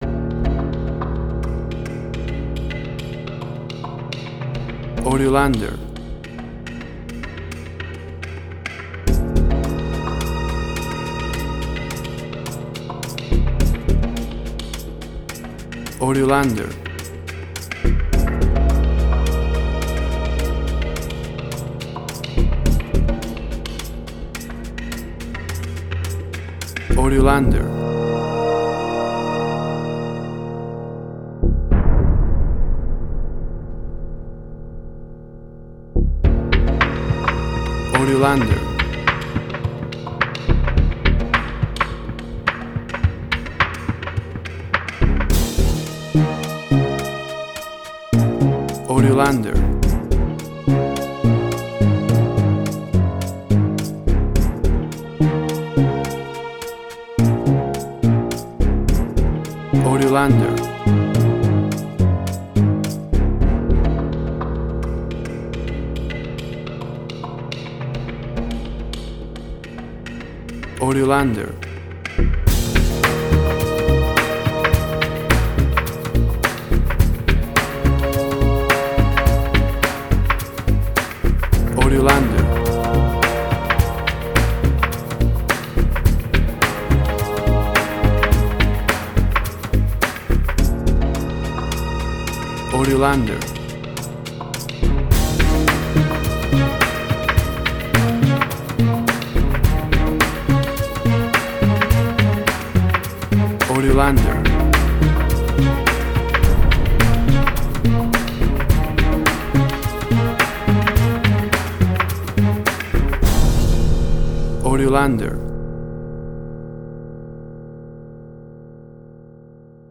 Suspense, Drama, Quirky, Emotional.
Tempo (BPM): 106